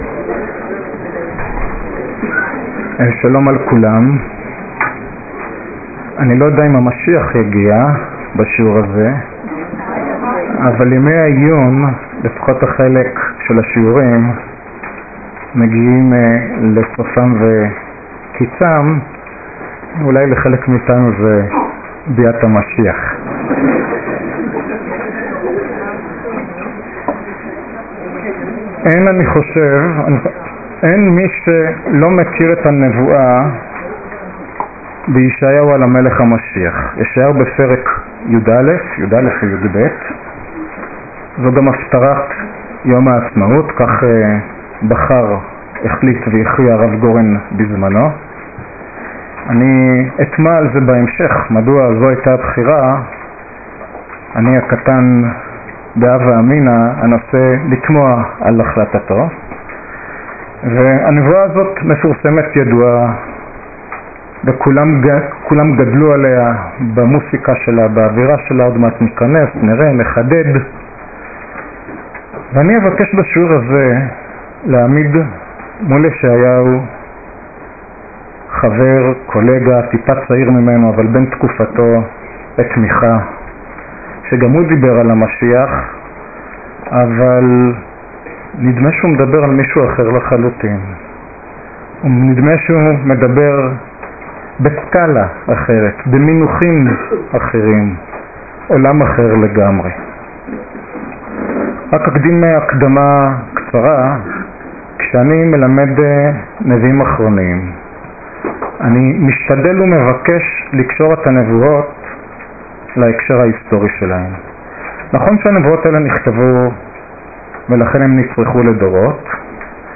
השיעור באדיבות אתר התנ"ך וניתן במסגרת ימי העיון בתנ"ך של המכללה האקדמית הרצוג תשס"ז